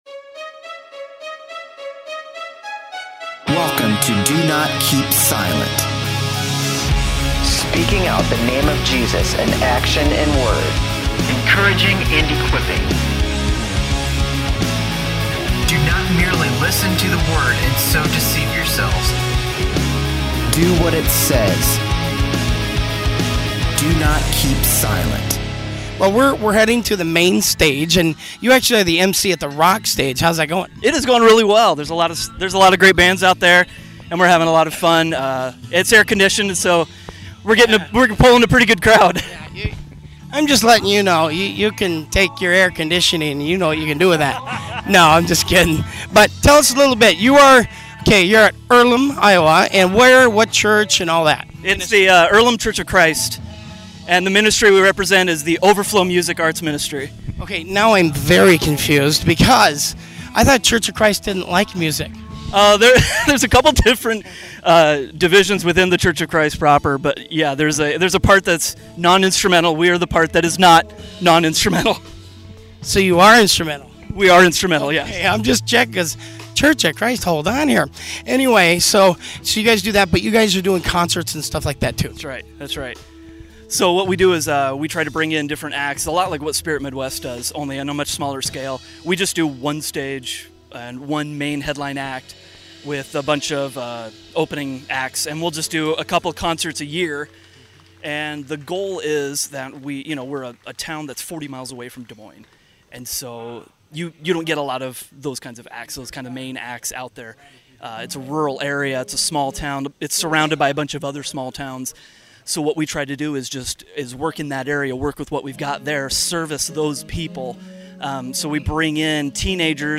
Truths & Convictions Posted by Worldview Warriors On Monday, September 26, 2011 0 comments This week, you'll hear interviews recorded from the Spirit Midwest Festival .